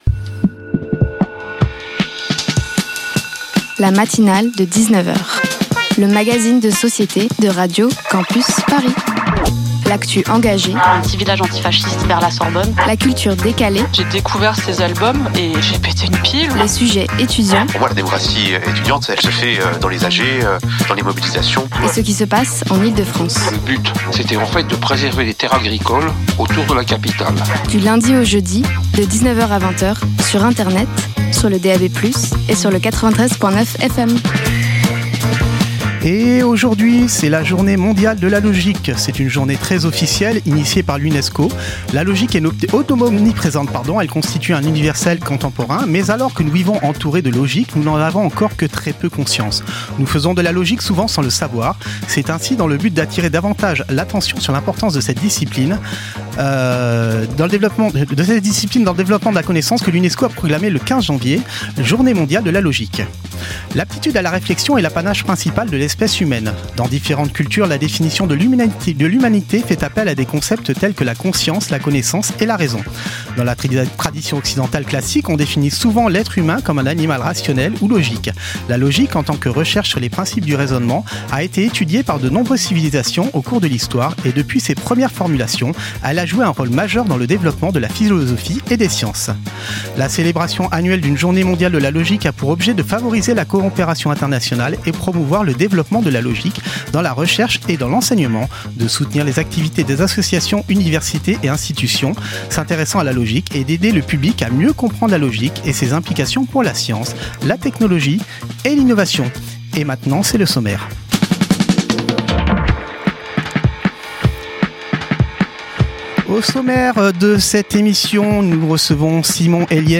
Financement de l'enseignement supérieur et La Coordination d'intimité dans le Cinéma Partager Type Magazine Société Culture jeudi 15 janvier 2026 Lire Pause Télécharger Ce soir